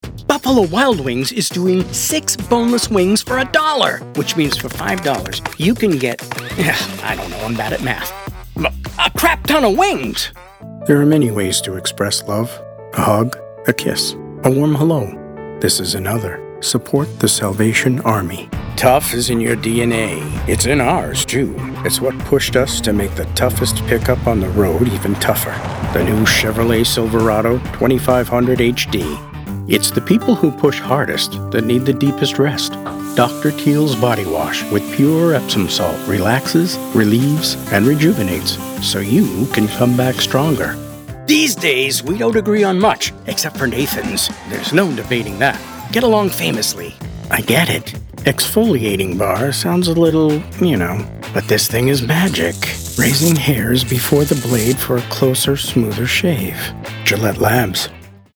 Commercial
Commercial Demo.mp3